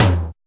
626 TOM2 LO.wav